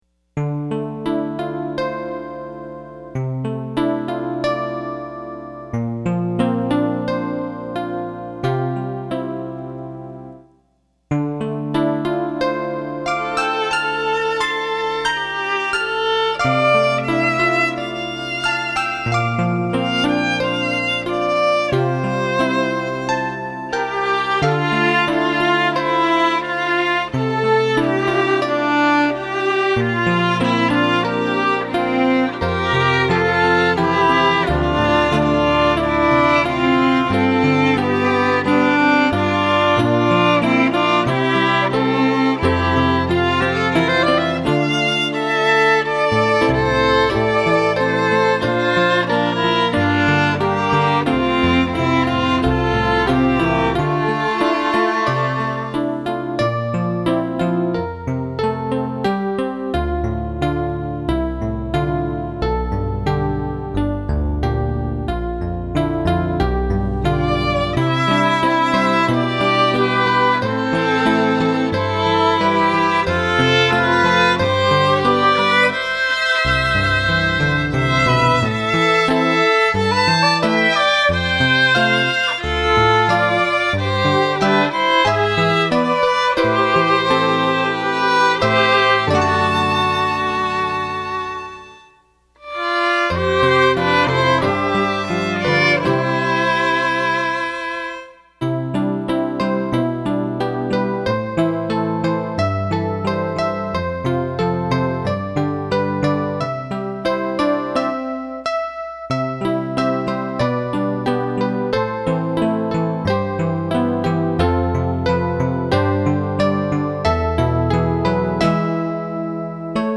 Voicing: String Duet and Piano